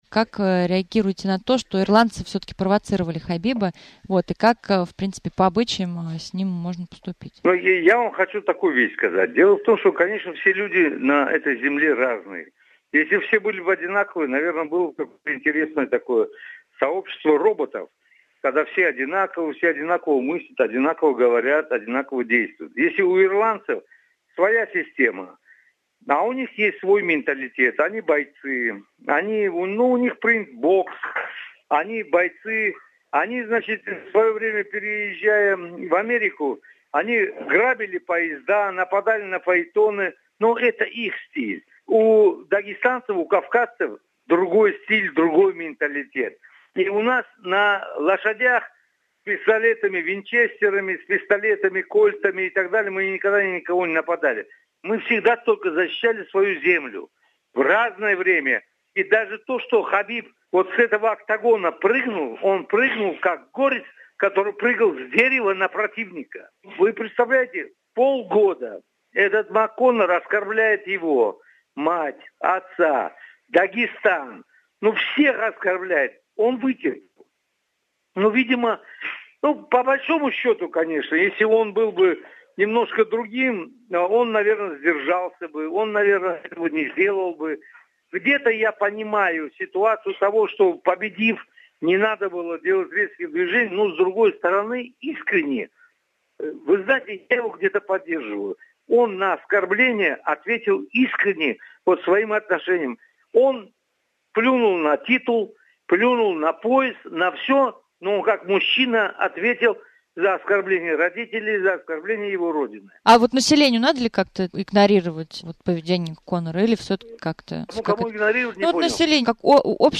Интервью с Гаджиметом Сафаралиевым от 8 июня 2018 года